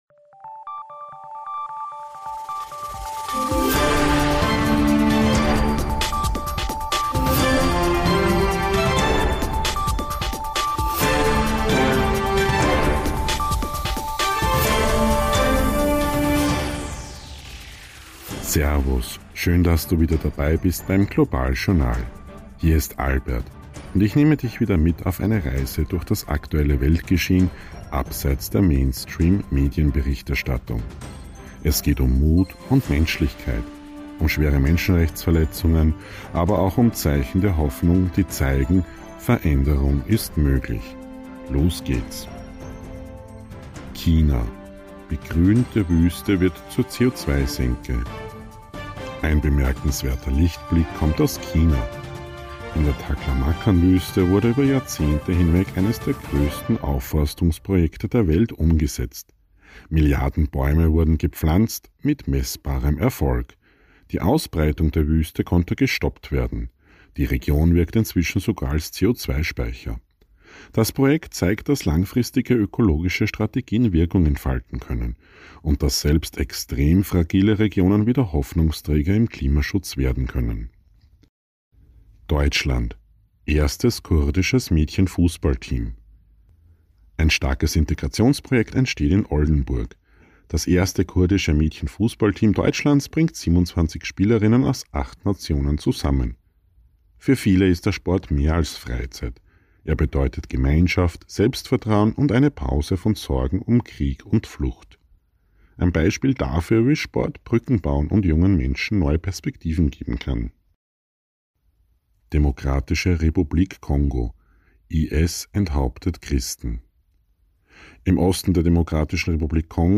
News Update März 2026